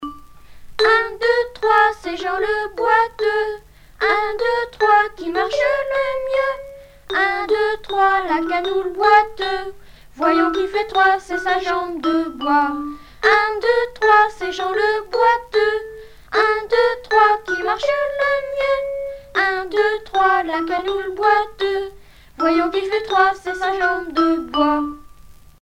Genre brève
Pièce musicale éditée